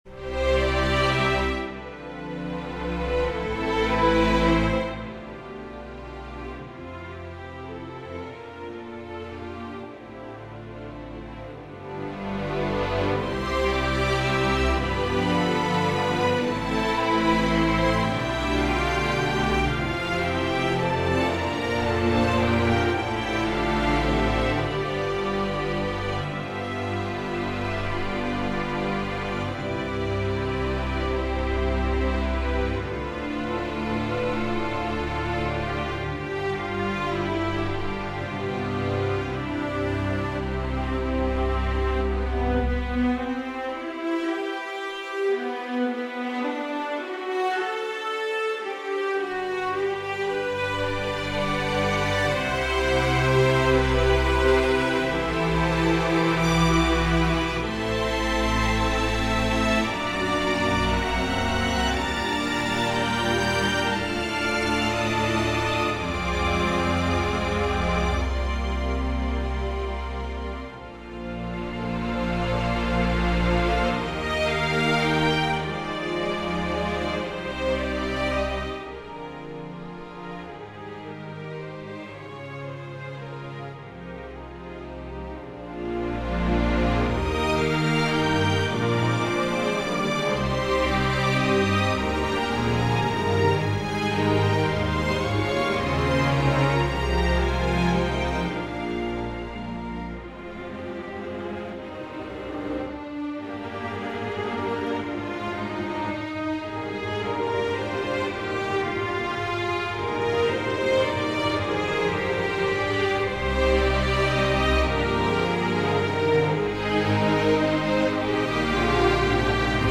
Doodle For String Orchestra